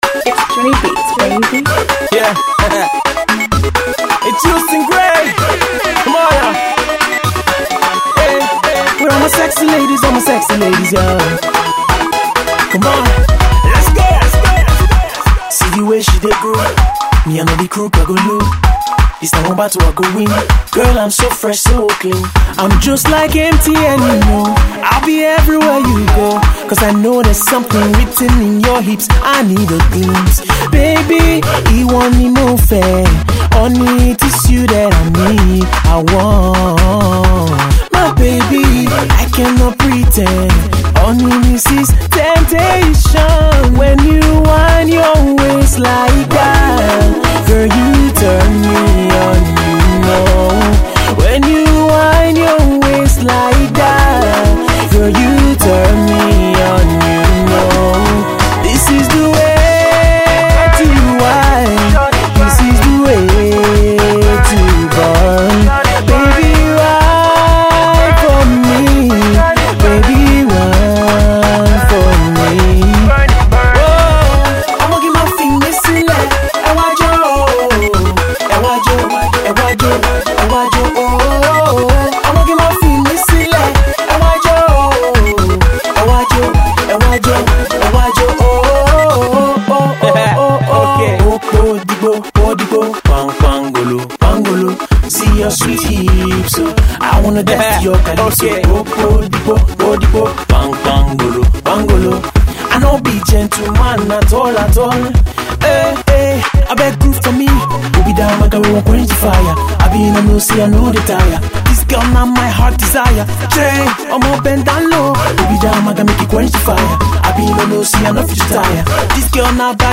He debuts with these two Afro-R&B joints